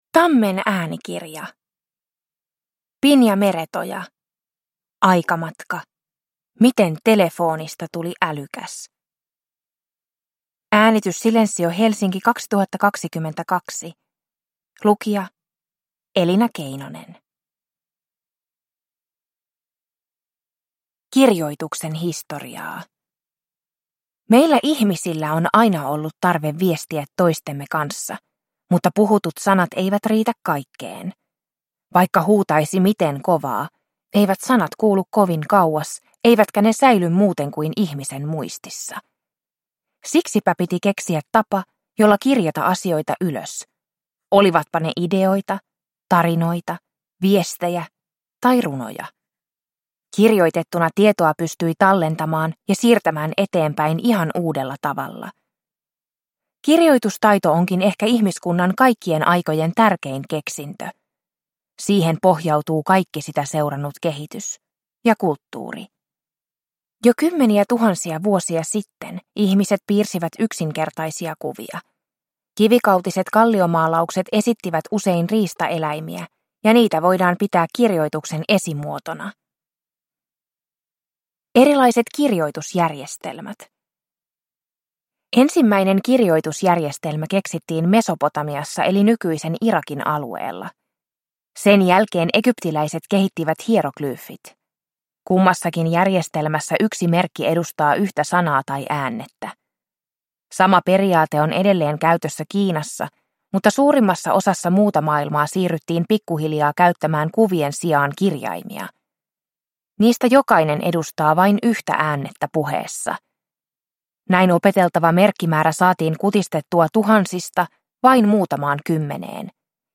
Aikamatka. Miten telefoonista tuli älykäs – Ljudbok – Laddas ner